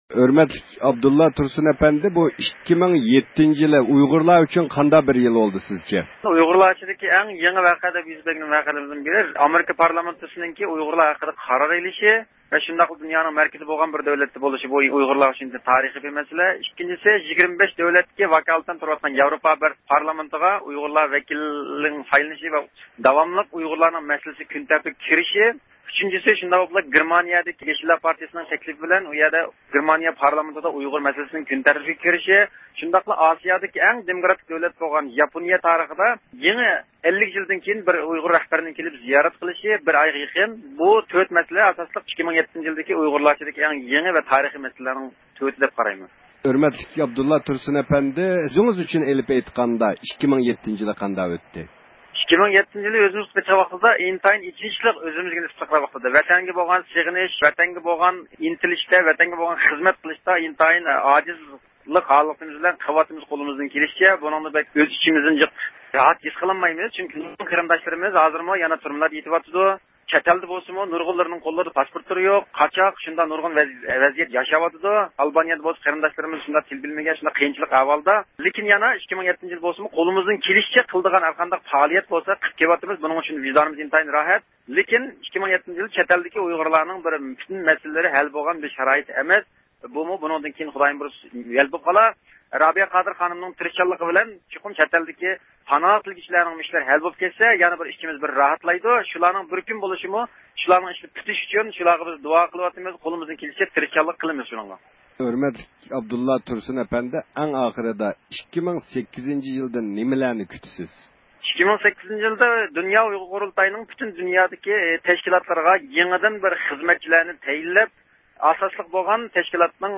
ئۇيغۇرلارنىڭ 2007- يىلىغا بەرگەن باھاسى ۋە 2008- يىلىدىن كۈتكەن ئۈمىدلىرىنى بىلىپ بېقىش ئۈچۈن تۈركىيىدىكى ئۇيغۇر پائالىيەتچىلەر ۋە ئۇيغۇر زىيالىيلىرى بىلەن سۆھبەت ئېلىپ باردۇق.